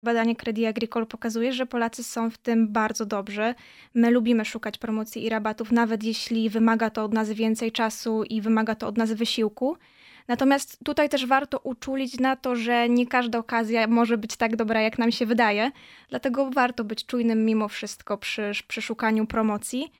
Rozmawiamy z ekspertką z Credit Agricole